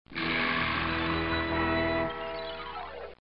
Death Sound Effect